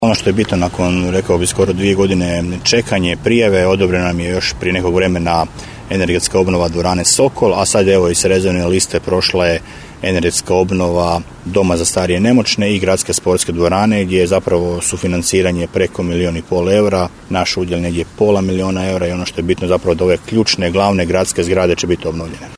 O projektima koji činom uručivanja Odluke uzlaze u postupak realizacije riječ je gradonačelnika Daruvara Damira Lneničeka